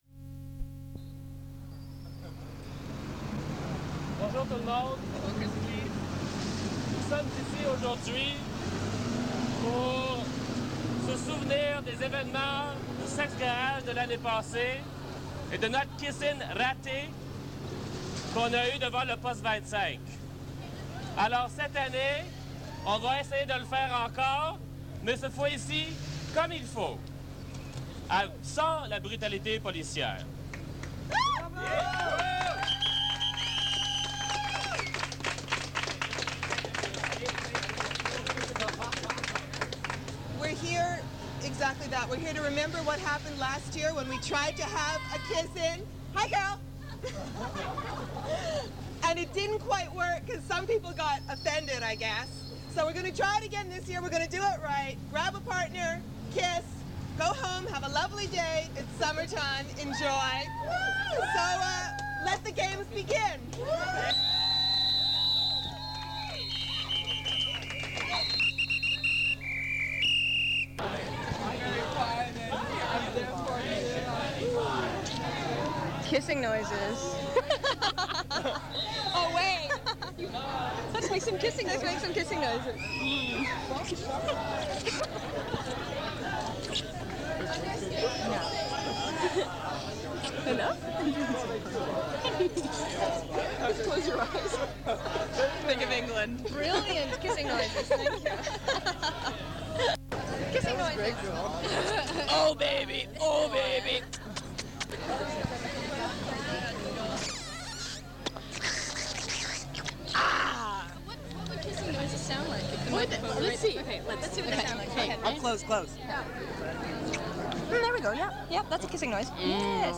It is a live recording at a “kiss in” event at Sex Garage in Montreal to protest the police raid and violence that took place in 1990.
They also interview “kiss in” participants.